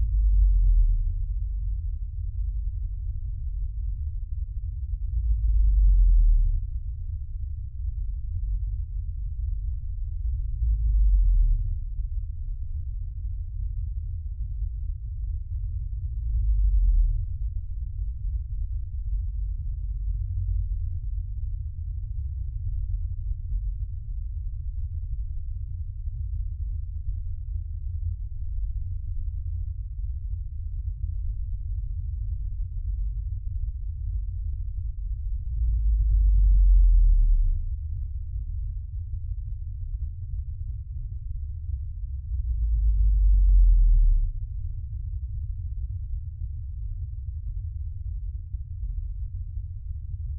Blue whale – Balaenoptera musculus
La baleine bleue produit des sons variés comparables à ceux des cétacés à dents (6 à 31kHz) et des
gémissements de basse fréquence (0,01 à 0,4kHz) qui se propagent sur des centaines de kilomètres de distance.
BlueWhale.wav